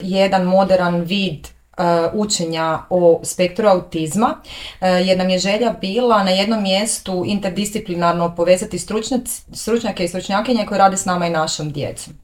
ZAGREB - Uoči četvrtog izdanja konferencije Autizam i mentalno zdravlje, u Intervjuu Media Servisa razgovarali smo